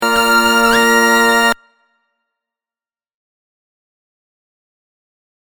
(don’t tell them…it’s a surprise)  I did a quick text alert last week, aiming for maximum obnoxiousness.  Please let me know what do you think (it’s 8 trumpets-Bass Trumpet, Alto Flugelhorn, Alto Trumpet, Flugelhorn, Cornet, and 3 Bb trumpets):